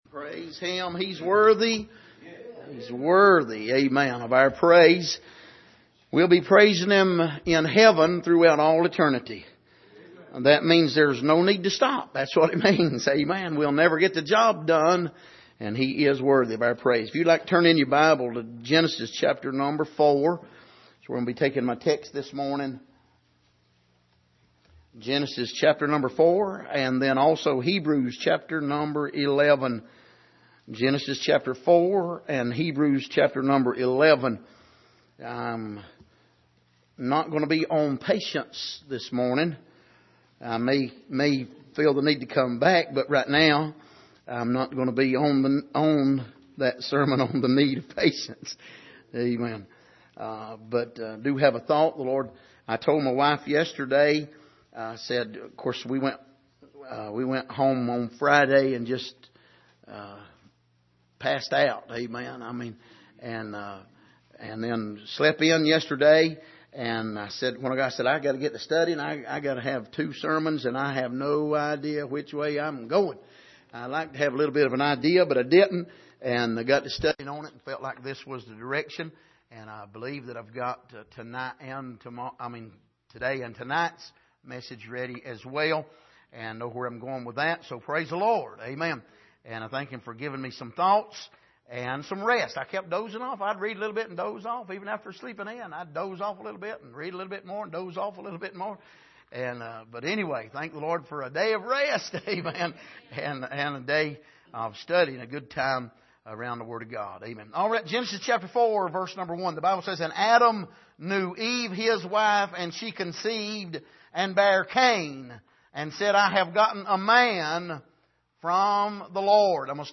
Passage: Genesis 4:1-10 Service: Sunday Morning